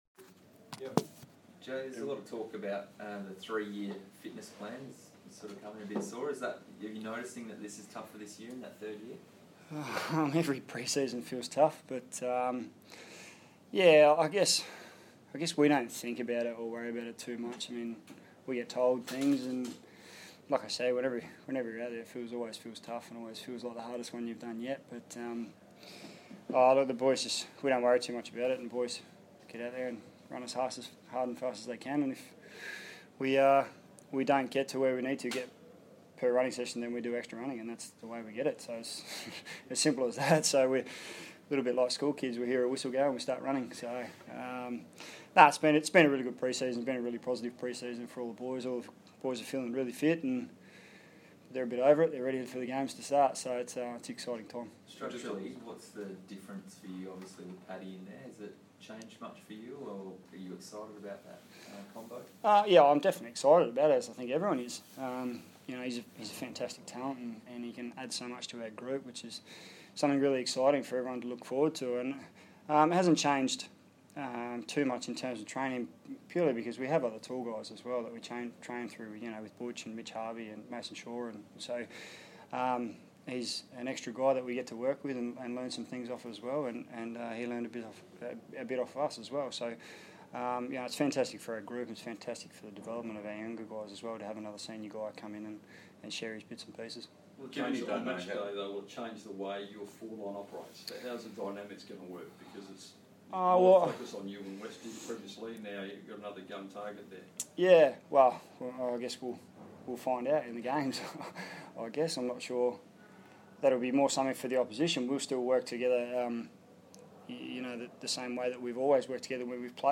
Jay Schulz press conference - Monday 23 February, 2015